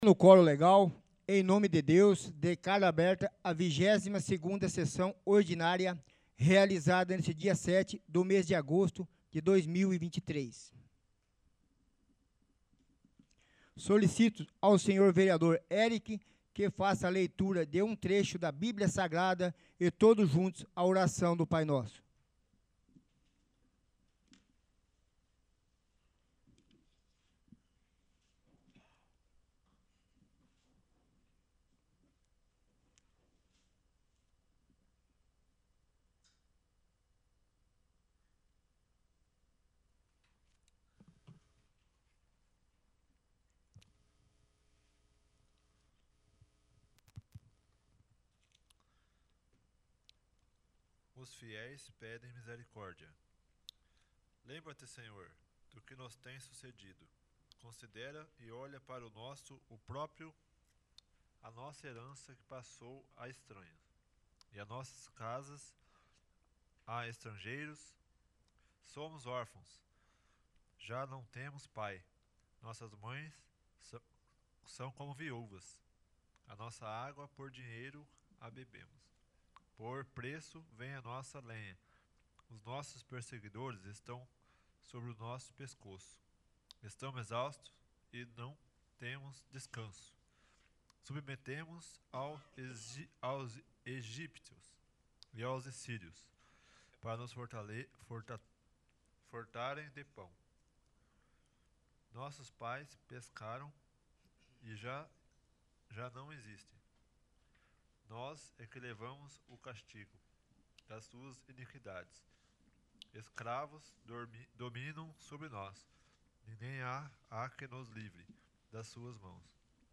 22º. Sessão Ordinária